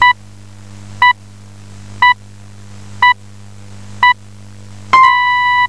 lucidity-radiopips100.wav